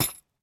latest / assets / minecraft / sounds / block / chain / step3.ogg